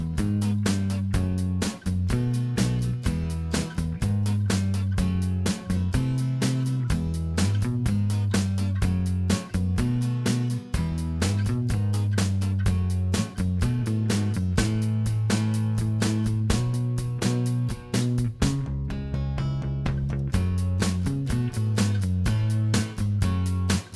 Minus Lead Electric Guitar Pop (1960s) 2:10 Buy £1.50